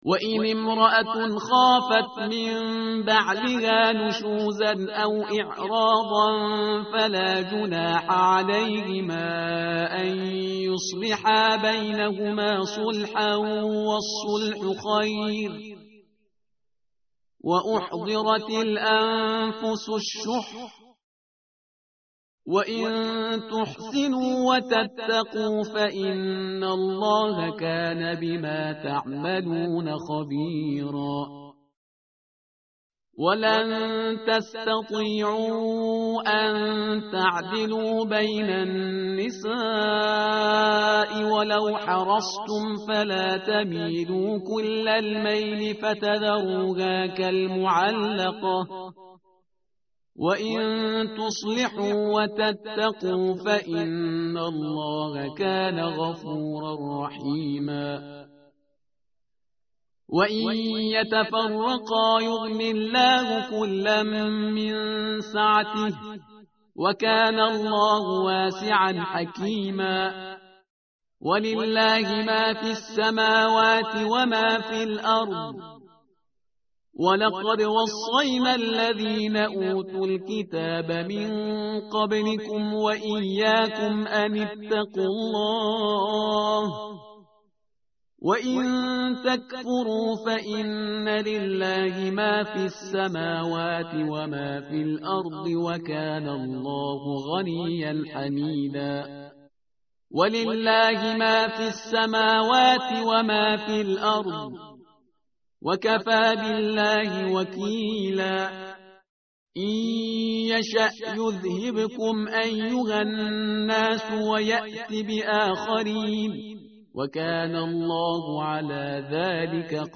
متن قرآن همراه باتلاوت قرآن و ترجمه
tartil_parhizgar_page_099.mp3